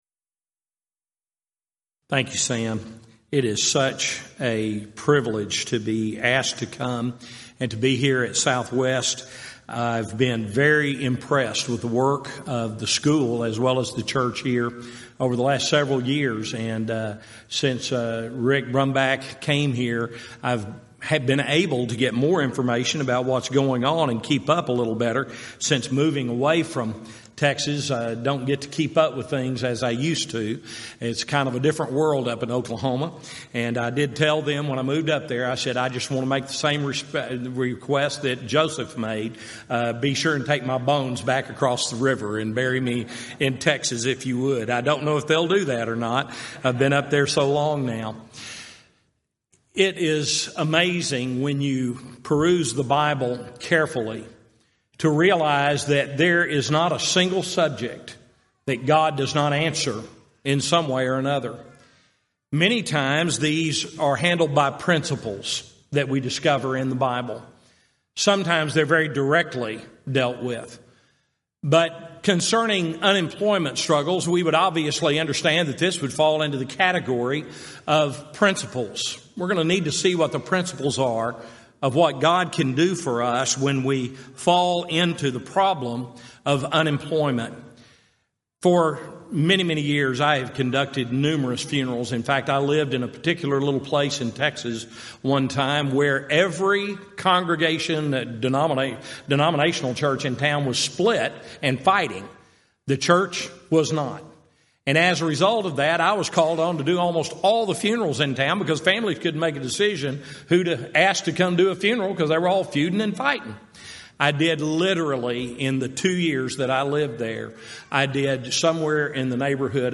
Event: 34th Annual Southwest Lectures
this lecture